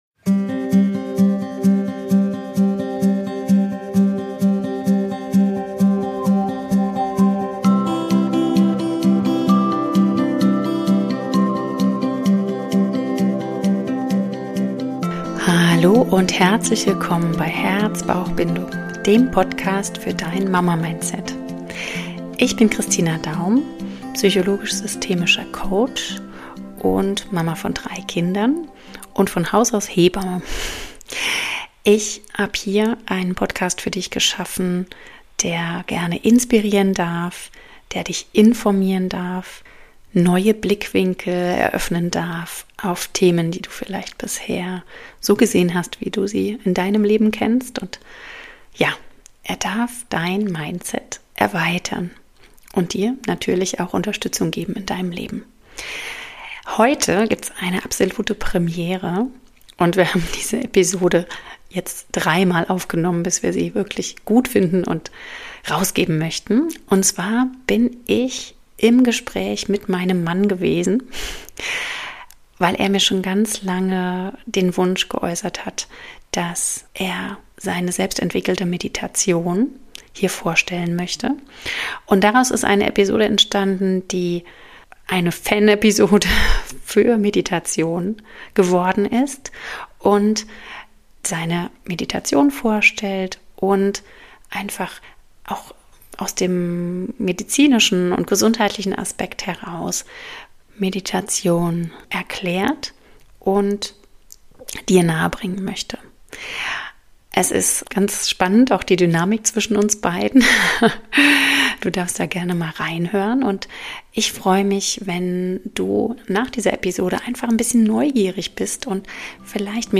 Dies ist eine ganz besondere Podcast Episode.